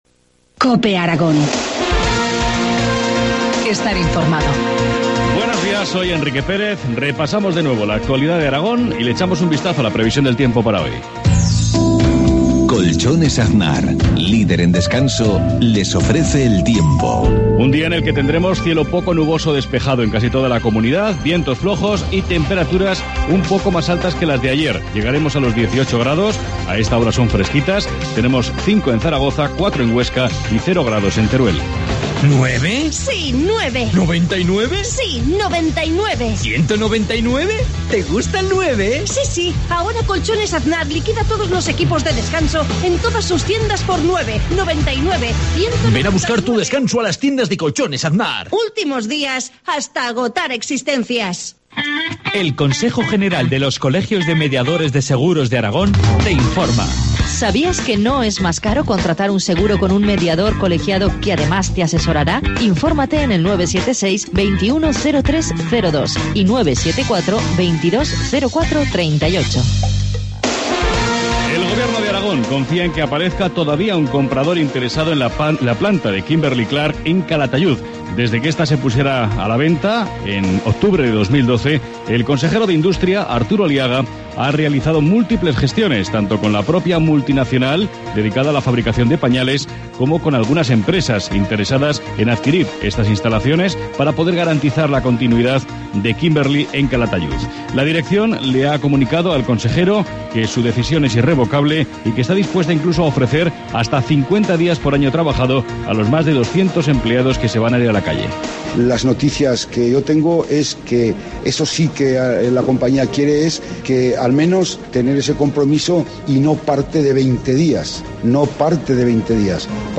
Informativo matinal, jueves 21 de marzo, 7.53 horas